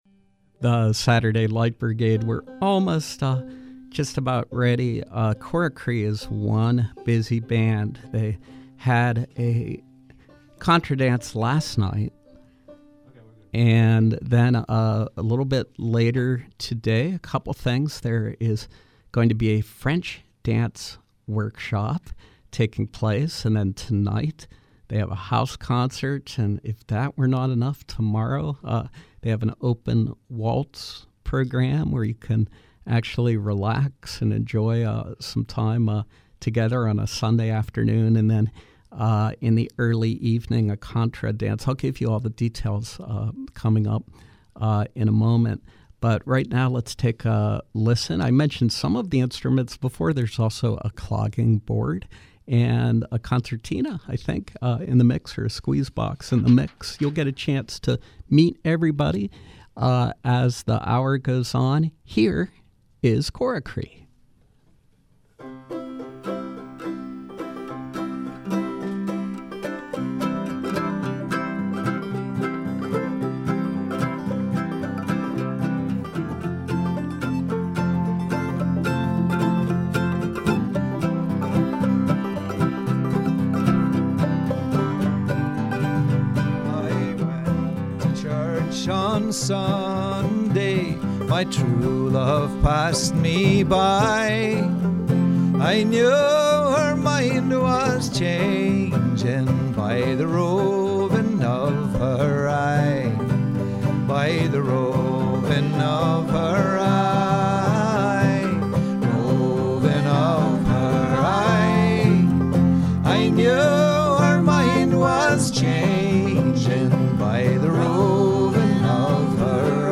Old time music